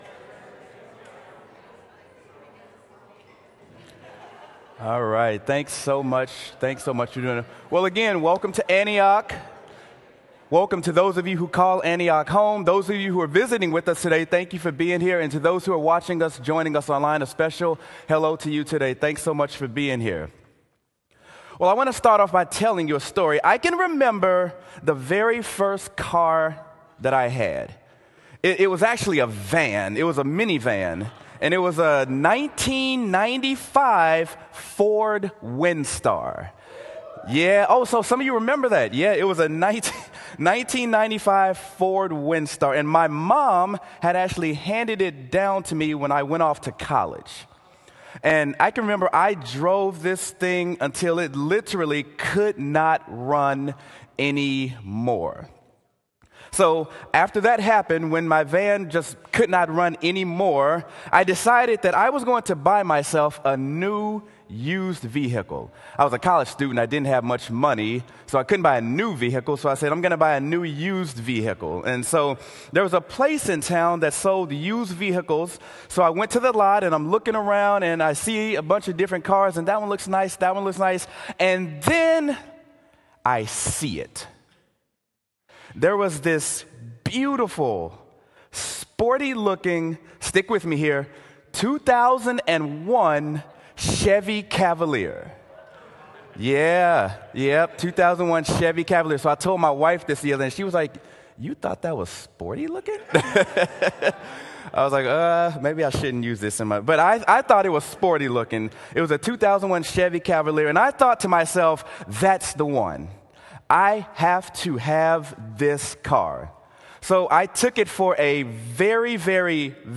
Sermon: Mark: Clean Hands and Dirty Hearts
sermon-mark-clean-hands-and-dirty-hearts.m4a